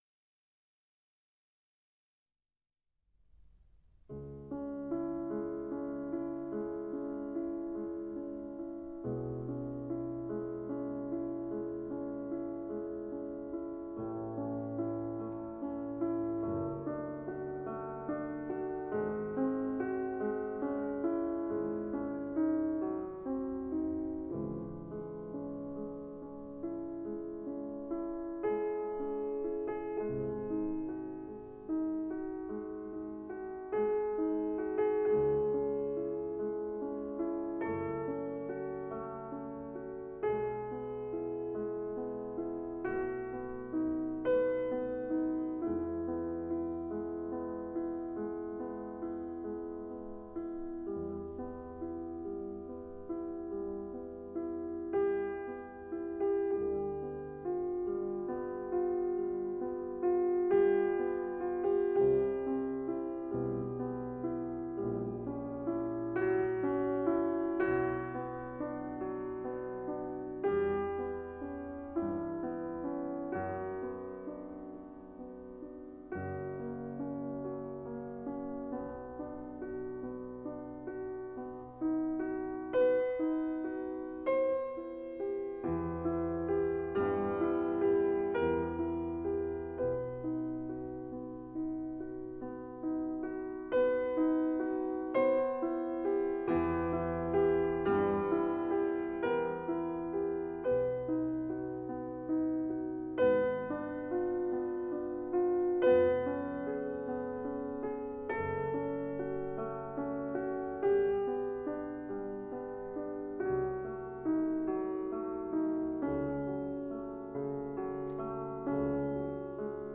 [2005-1-5]送几个古典曲子